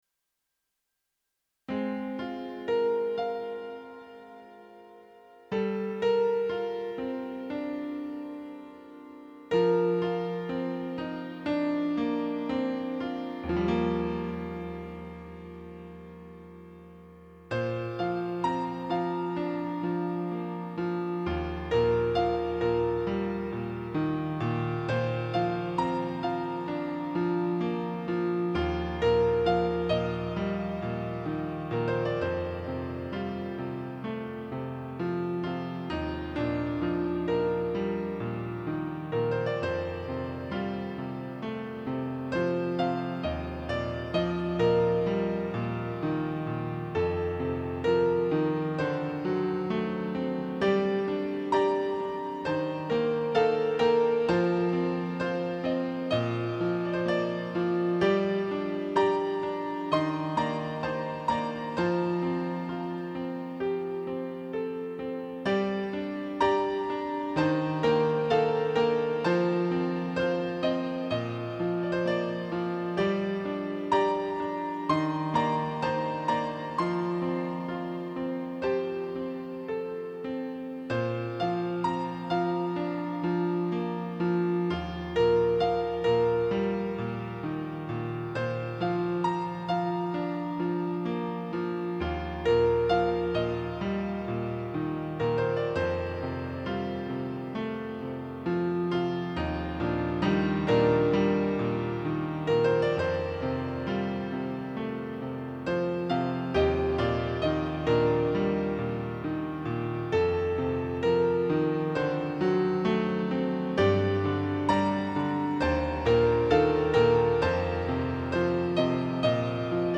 幻想的な星空を想像できる、美しく静かで、どこか壮大なピアノ曲ですね。